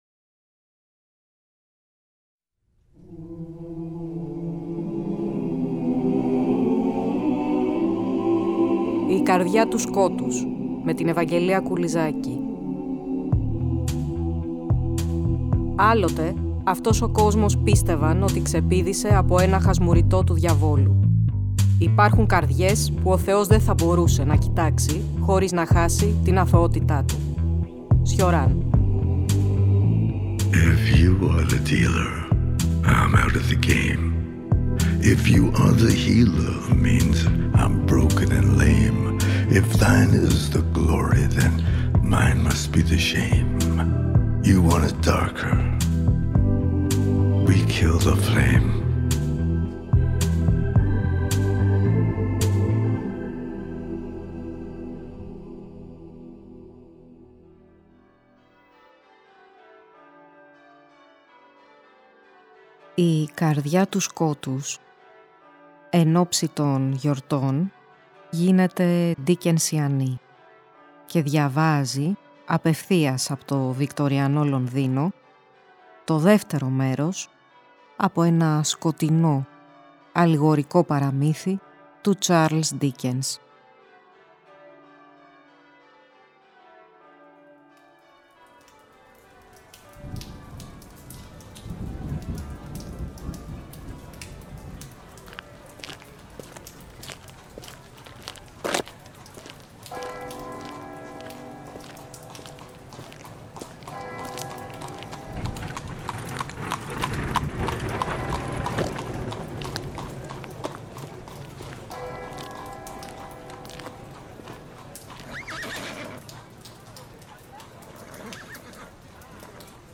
Στο σχετικά άγνωστο κείμενό του, ”Ιστορία Κανενός”, που διαβάζουμε στην εκπομπή, η ”λοξή” μυθοπλαστική ματιά του μεγάλου βικτοριανού συγγραφέα λειτουργεί ταυτόχρονα ως κοινωνικό σχόλιο, ανησυχητικά επίκαιρο και απροσδόκητα διαχρονικό.